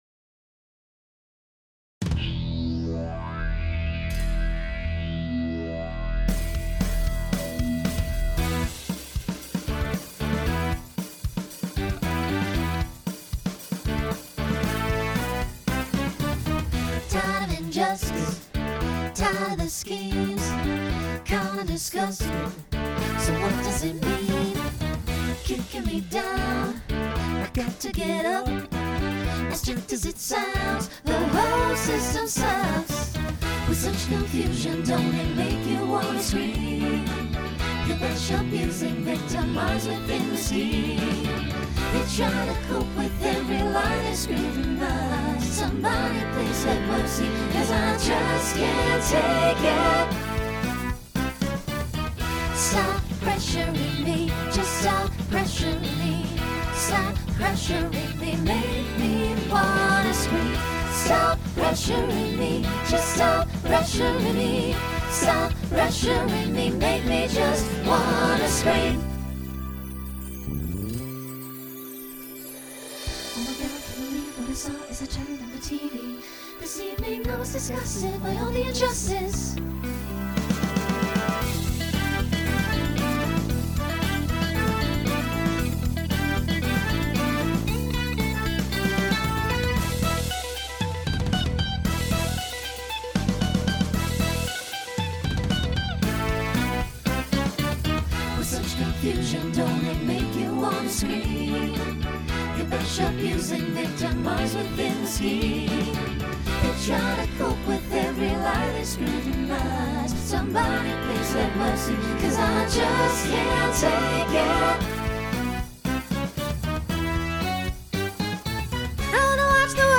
Genre Broadway/Film , Pop/Dance , Rock
Voicing SATB